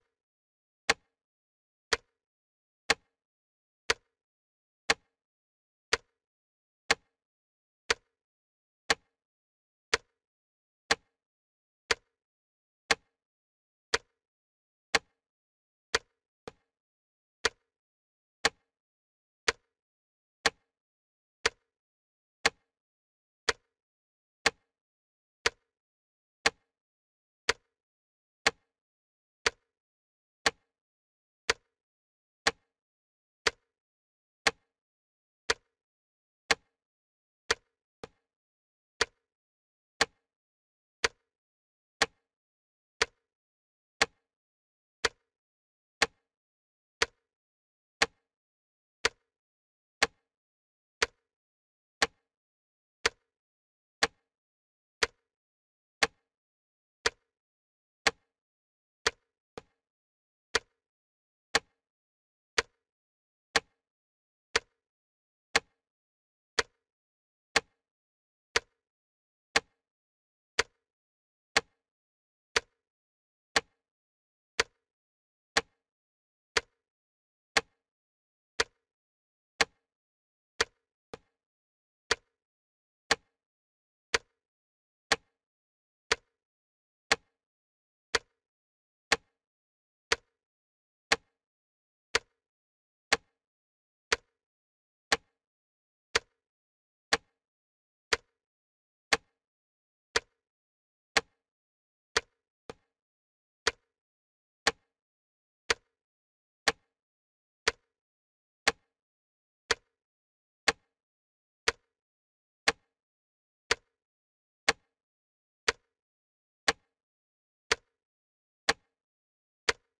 Эфир ведут Алексей Венедиктов и Сергей Бунтман